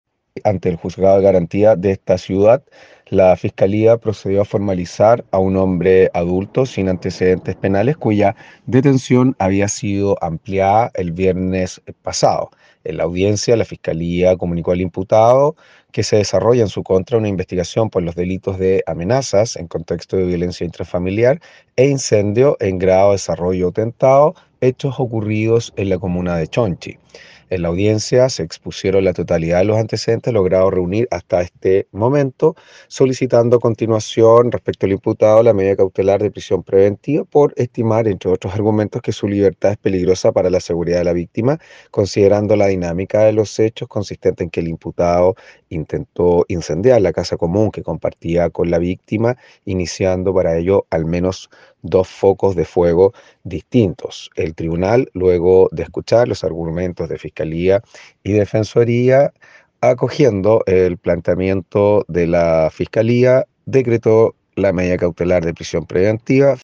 El fiscal adjunto de Castro, Fernando Metzner, señaló que en este episodio de violencia contra la mujer, el sujeto intentó prender fuego a la vivienda que habitaba junto a la víctima.